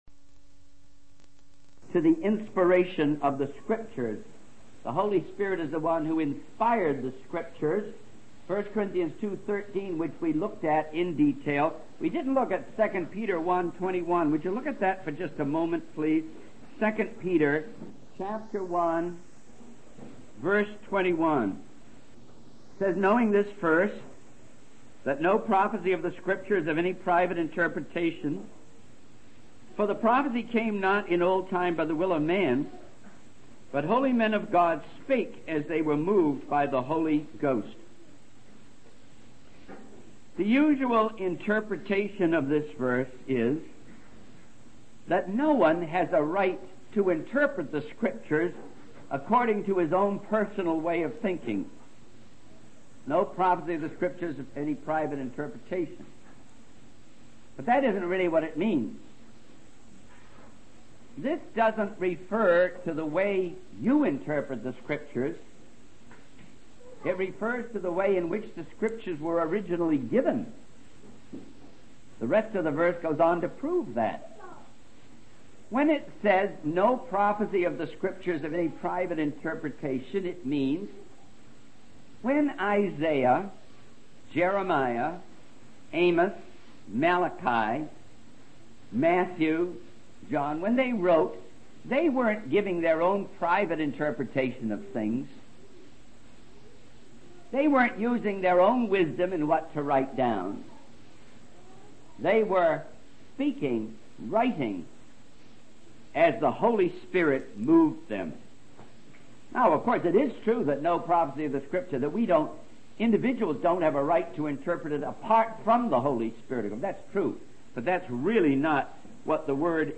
In this sermon, the speaker discusses the importance of accepting the Bible by faith and the authority it holds in preaching the word of God. He emphasizes that one cannot argue people into the kingdom of God, but rather encourage them to read the Bible and experience its living materials. The speaker also addresses the topic of money, stating that Jesus talked more about money than heaven and hell, and challenges the notion that everyone should be prospered.